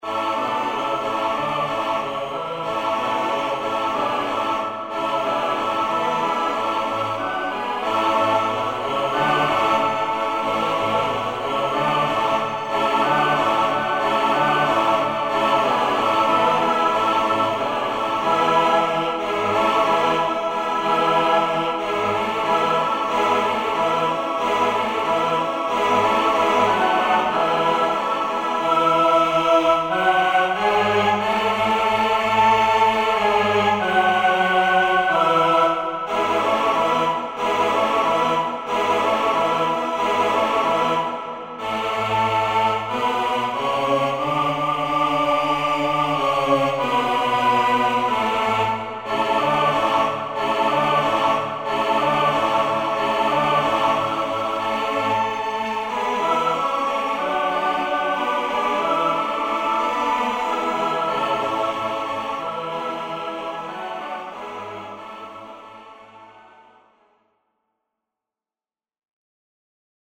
Eight Voice Choir
Certainly one of the major 'classic' Mellotron sounds, and perhaps the most arresting, this recording of four females and four males was first used in the early 1970s with the advent of the M400 and was widely used by rock groups who found that the sound of a Mellotron was a bit better than the sound of their chronic off-key harmonising.
The strain on the vocal performers shows up in two places on this recording; on the upper registers the male voices repeat the last octave they sang - mostly because the castratii failed to show up on the day - whereas on the lower end the female voices sound like they are either on Quaaludes or trying to gatecrash a Masonic party.